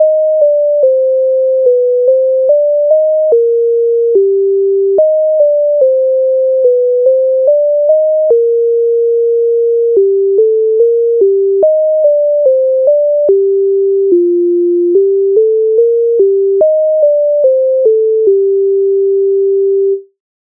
MIDI файл завантажено в тональності g-moll
Ой піду я в ліс по дрова Українська народна пісня з обробок Леонтовича с. 125
Ukrainska_narodna_pisnia_Oj_pidu_ia_v_lis_po_drova.mp3